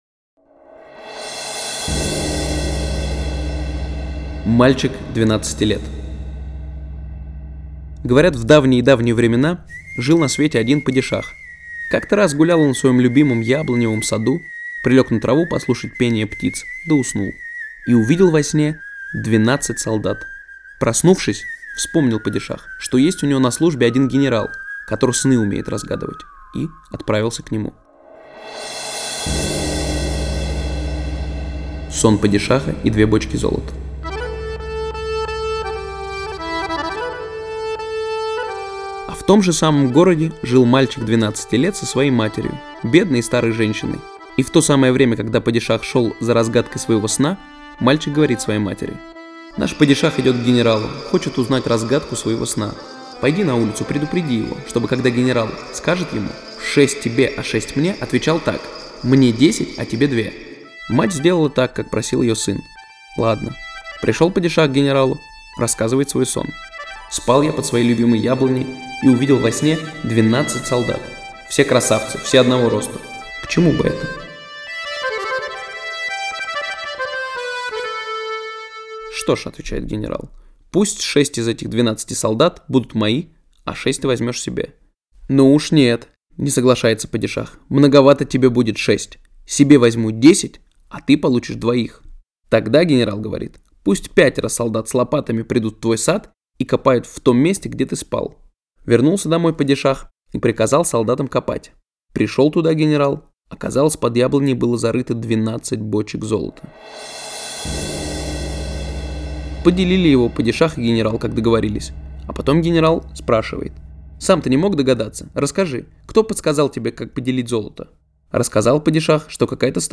Мальчик двенадцати лет - татарская аудиосказка - слушать онлайн